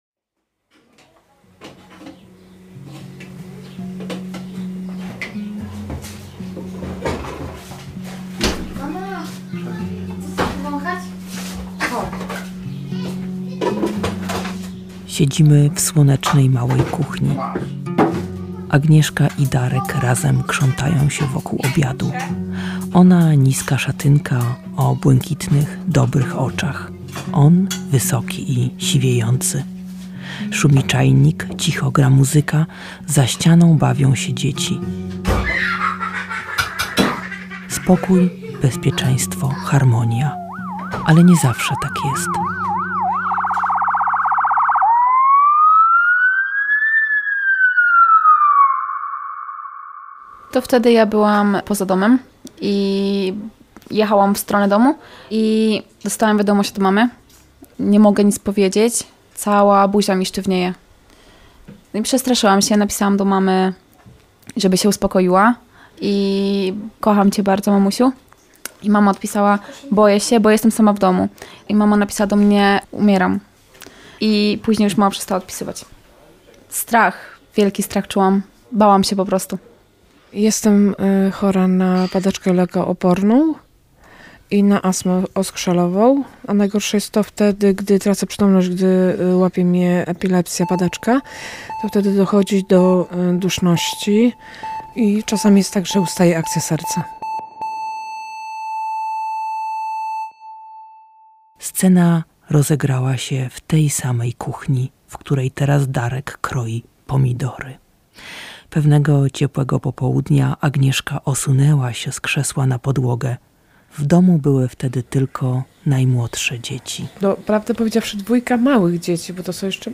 Tam przy mruczącym czajniku opowiada się najlepiej.
Tagi: reportaż